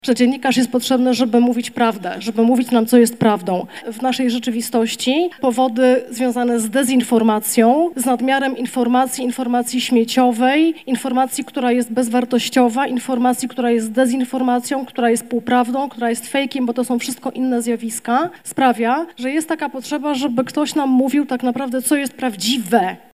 Prezenterka telewizyjna  zaznaczyła, że obecnie wielkim wyzwaniem jest selekcja treści, które do nas docierają. A w  dziennikarstwie najważniejsze jest szukanie prawdy – dodała Agnieszka Gozdyra.